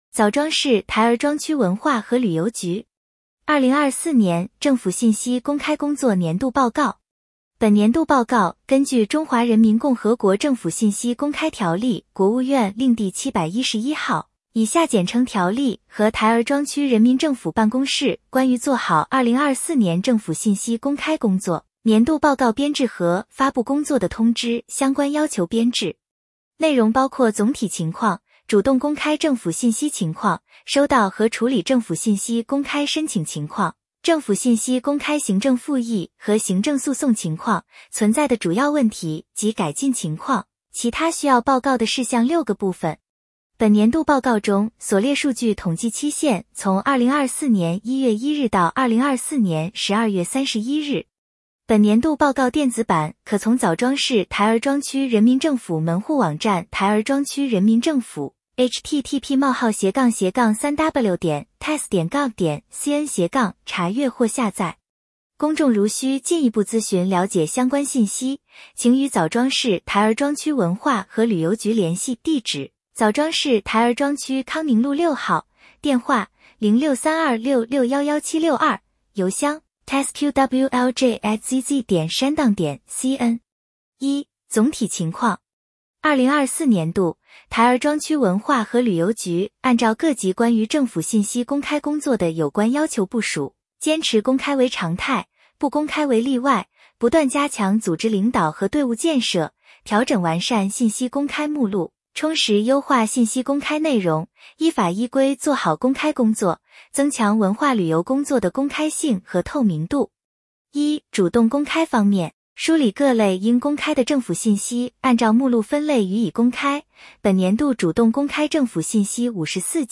点击接收年报语音朗读 枣庄市台儿庄区文化和旅游局2024年政府信息公开工作年度报告 作者：台儿庄区文化和旅游局 来自：台儿庄区文化旅游局 时间：2025-01-24 本年度报告根据《中华人民共和国政府信息公开条例》(国务院令第711号,以下简称《条例》)和《台儿庄区人民政府办公室关于做好2024年政府信息公开工作年度报告编制和发布工作的通知》相关要求编制。